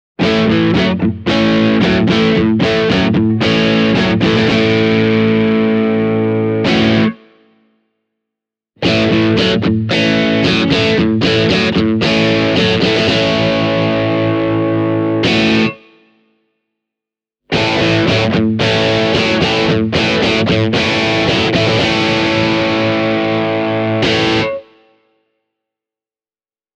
The tone of the Flaxwood MTQ doesn’t come as a surprise – this model offers an array of very tasty Tele-style sounds!
flaxwood-mtq-hybrid-e28093-drive.mp3